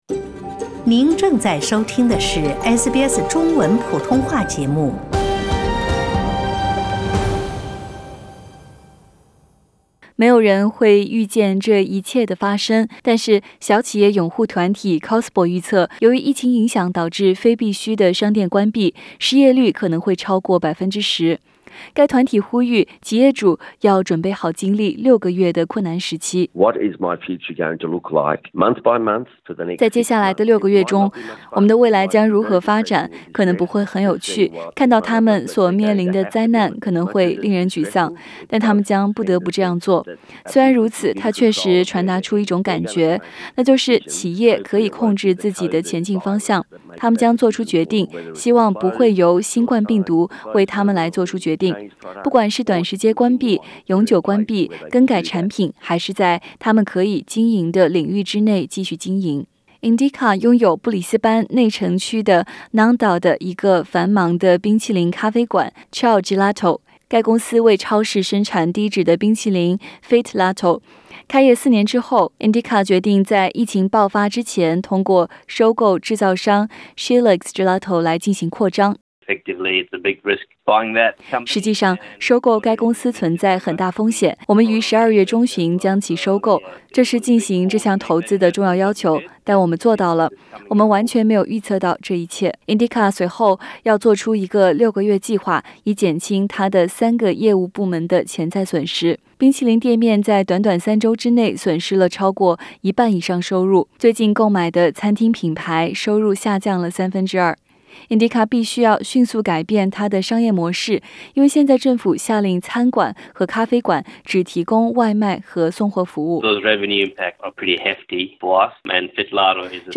点击上方图片收听录音报道。